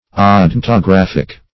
odontographic - definition of odontographic - synonyms, pronunciation, spelling from Free Dictionary
\O*don`to*graph"ic\
odontographic.mp3